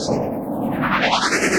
Index of /musicradar/rhythmic-inspiration-samples/150bpm
RI_ArpegiFex_150-03.wav